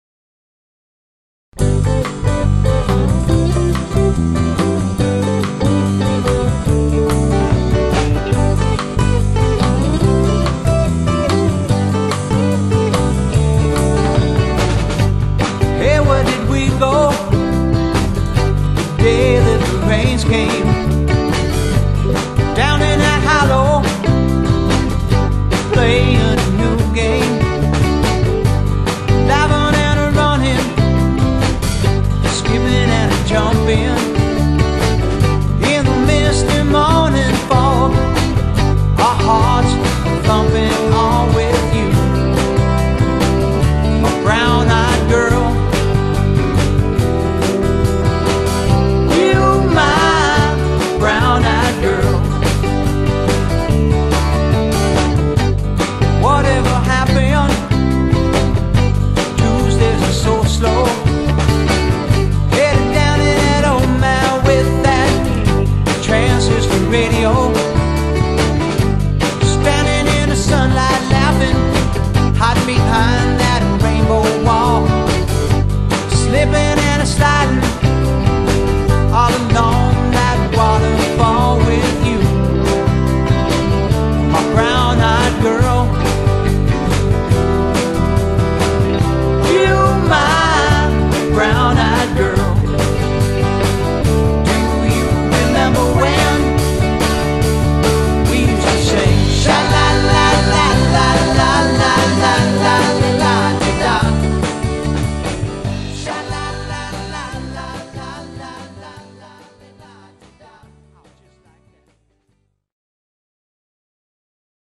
This is the ultimate variety band.